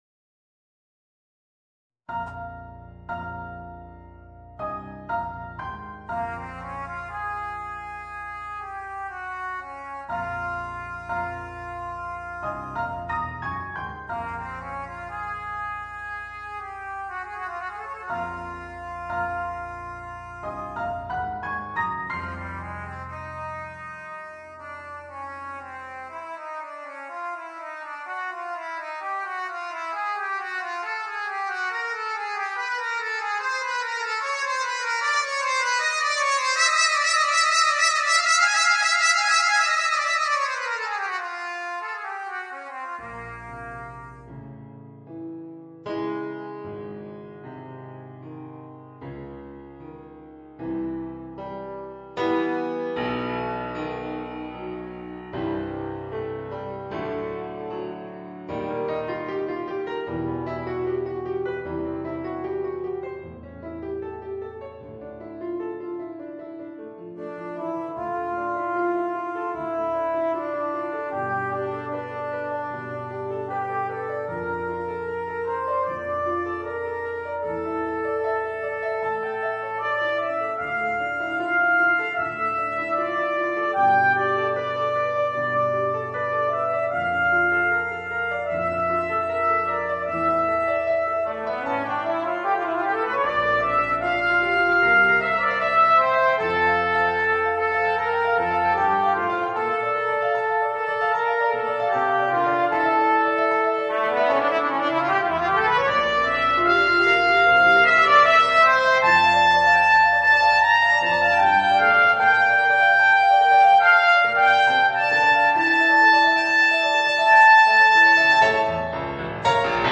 Trompete & Klavier